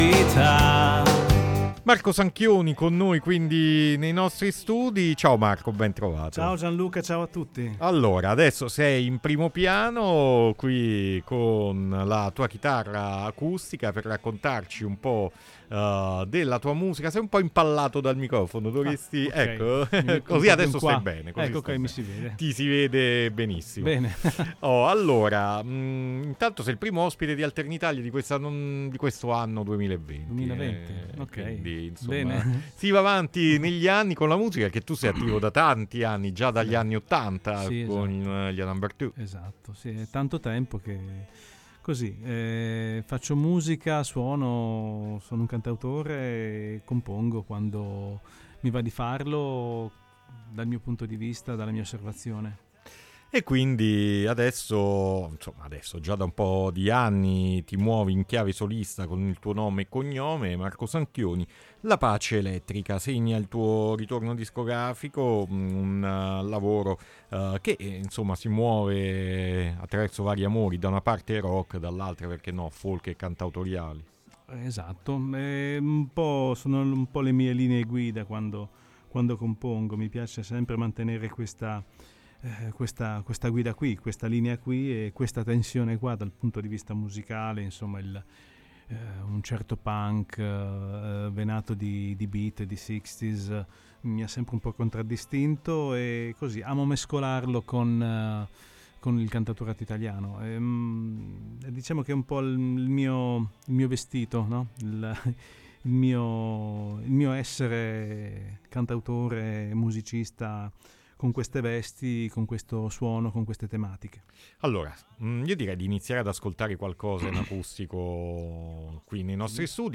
suonando anche tre brani live in acustico nei nostri studi.